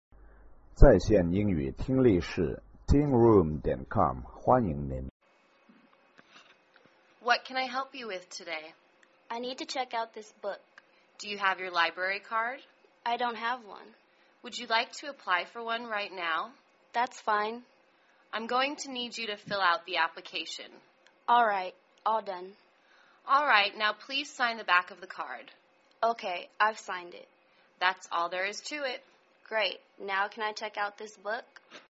英语情景对话-Applying for a Library Card(1) 听力文件下载—在线英语听力室